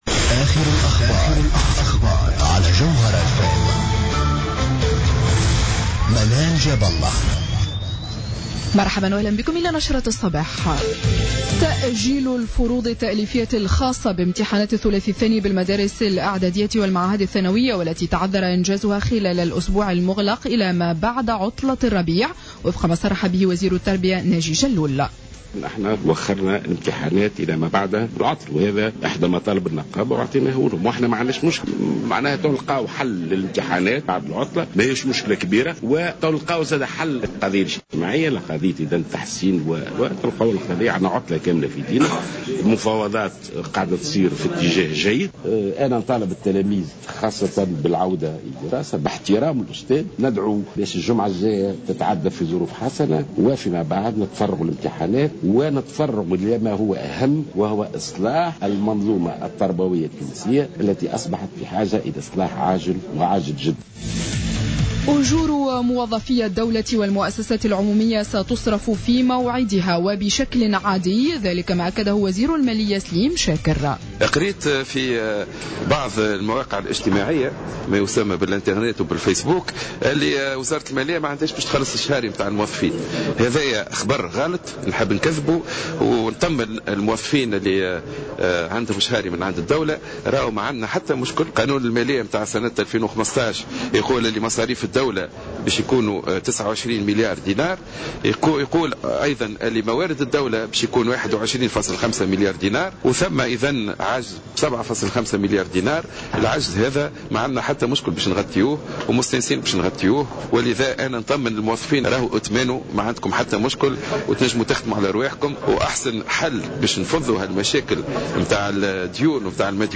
نشرة أخبار السابعة صباحا ليوم السبت 07 مارس 2015